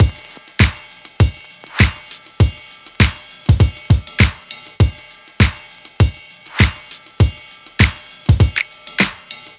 Unison Funk - 3 - 100bpm.wav